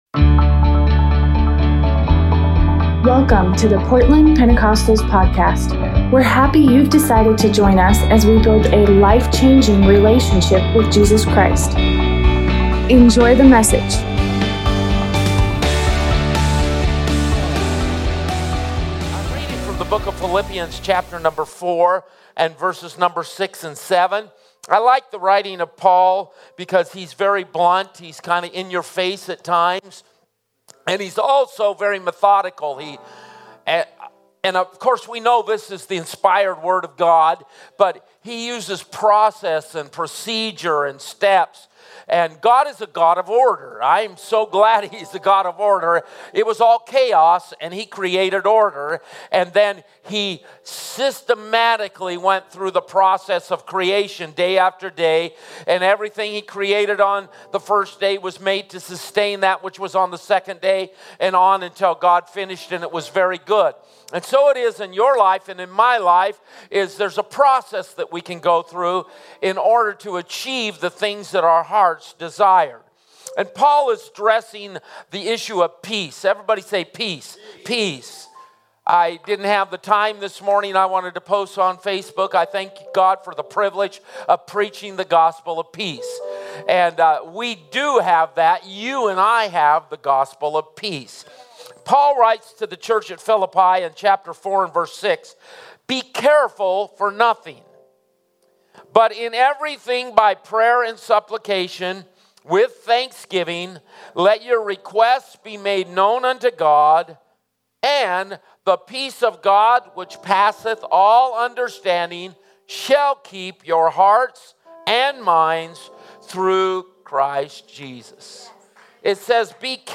Sunday sermon